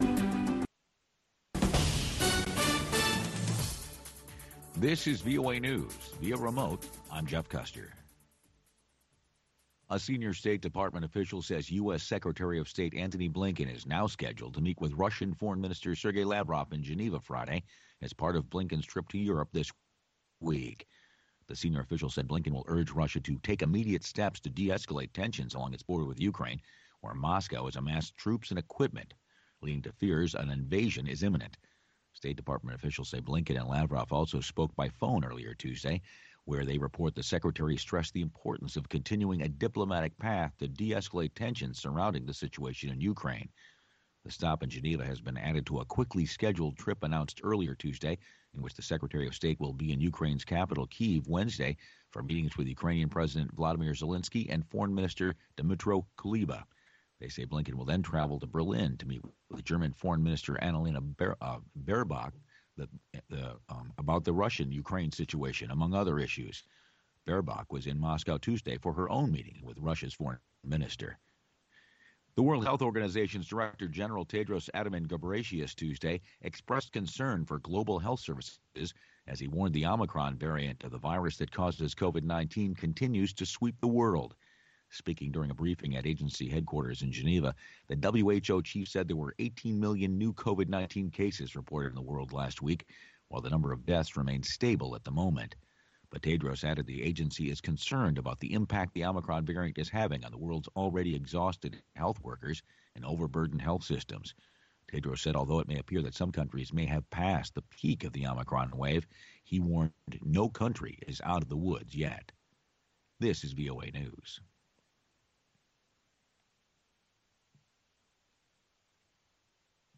Embed VOA Newscasts Embed The code has been copied to your clipboard.
Around the clock, Voice of America keeps you in touch with the latest news. We bring you reports from our correspondents and interviews with newsmakers from across the world.